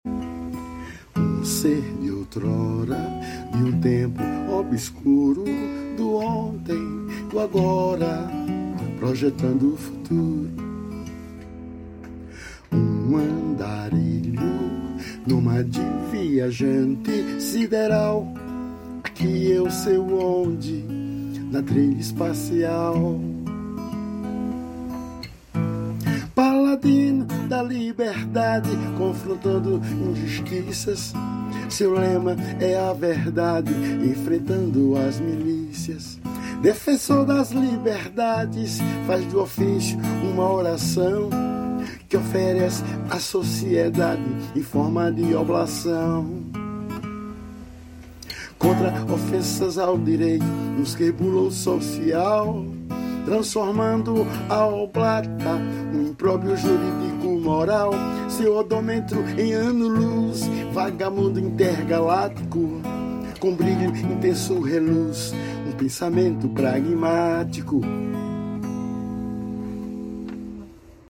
EstiloJazz